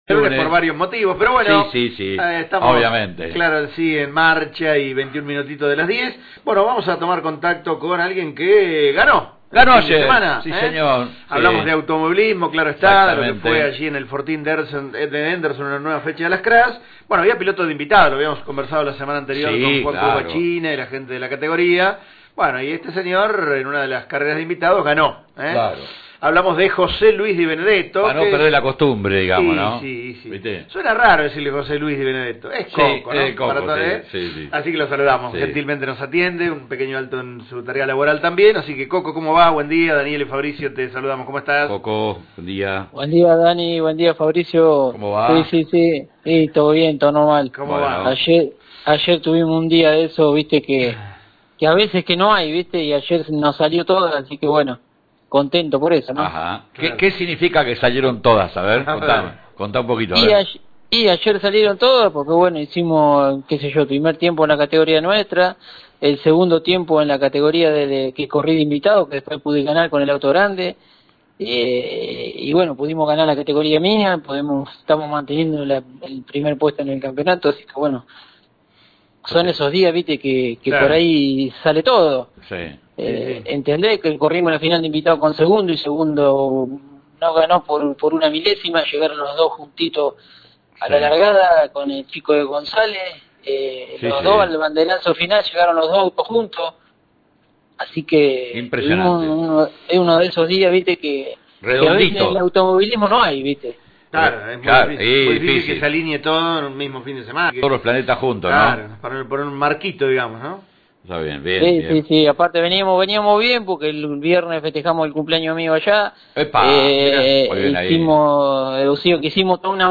Fuentes: Entrevista en La Mañana de Del Sol Pehuajó,